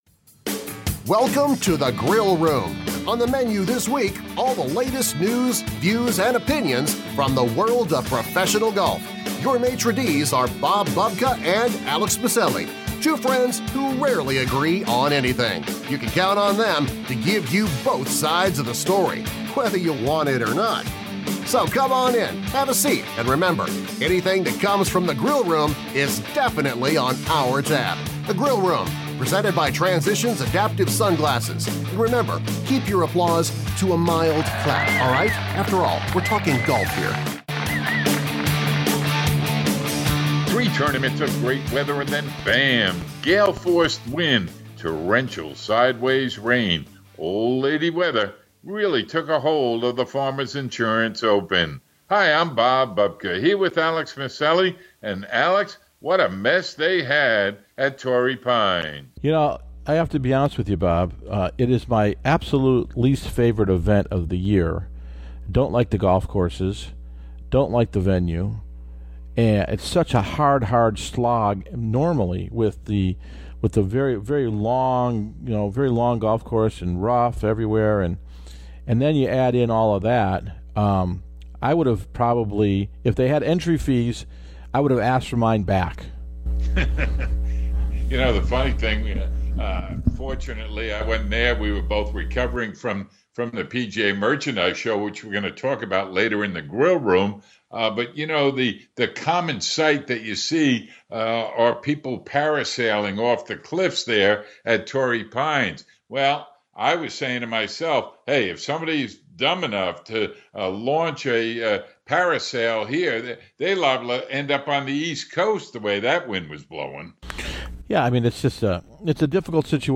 PGA Show recap and Feature Interview